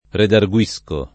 redarguire [redargu-&re o anche redargU&re secondo i casi] v.; redarguisco [redargu-&Sko o